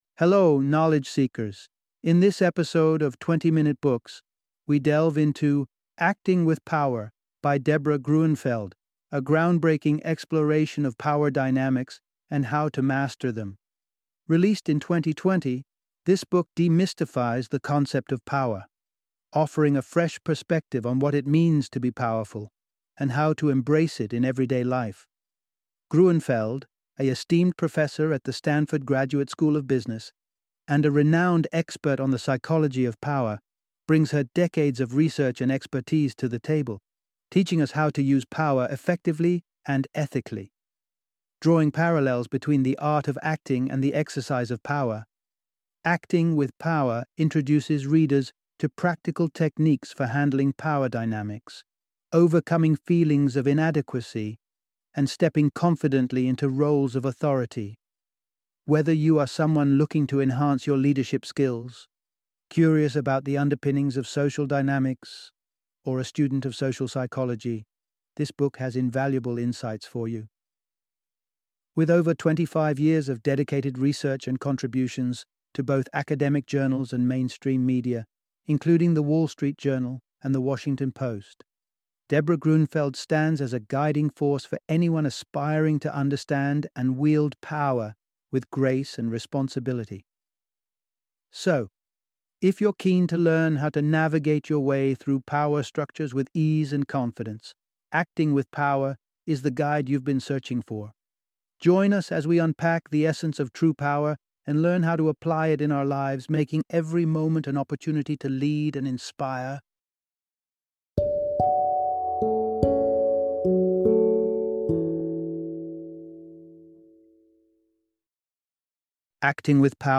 Acting with Power - Audiobook Summary